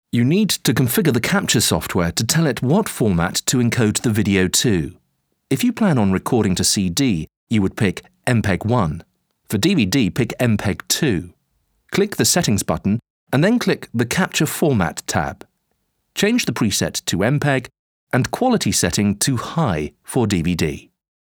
articulate, classic, contemporary, British English, standard BBC
Sprechprobe: eLearning (Muttersprache):
Warm, intelligent and compelling British voice for all audio applications